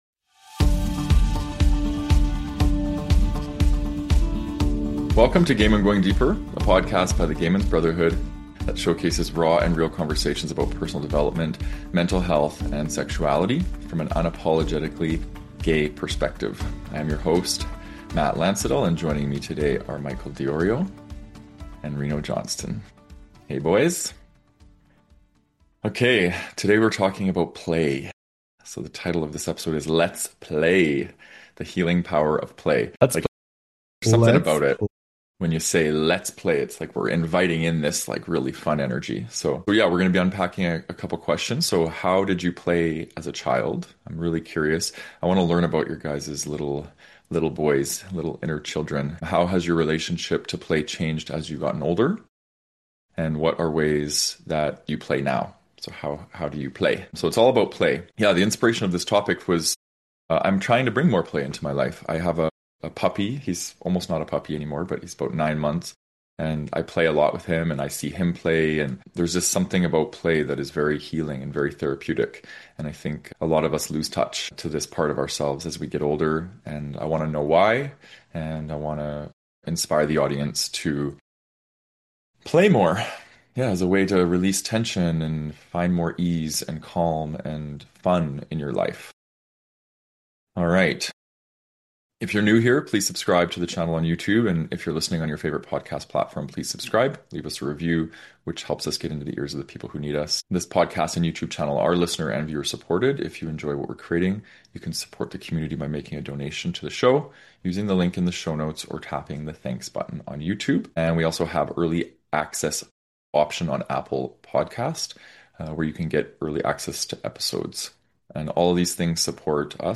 In this episode, we explore why so many adults lose their playful side and why reconnecting with it can be one of the most powerful ways to reclaim joy, authenticity, and connection. Some of the things we cover in this conversation are: How shame and self-consciousness disconnect us from play The difference between authentic play and performative fun How western culture slowly erodes our inner child Why